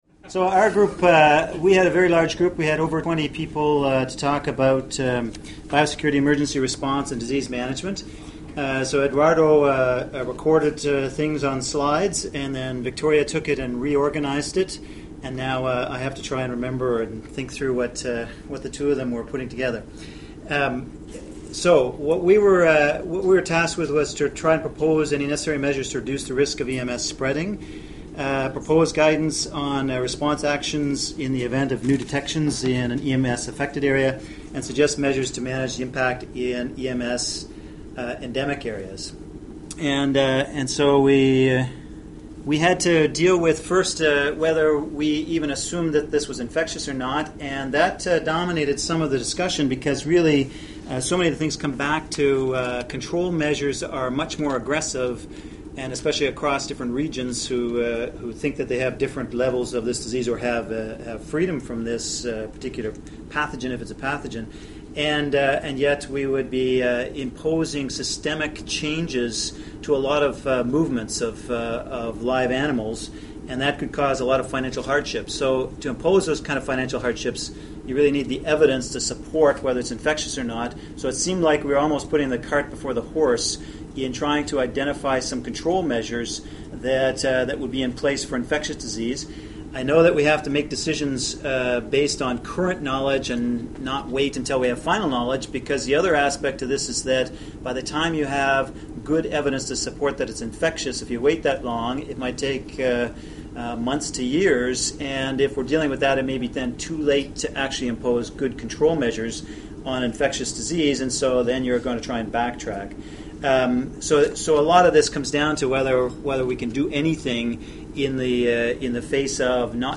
Group discussion on AHPNS biosecurity, emergency response and disease management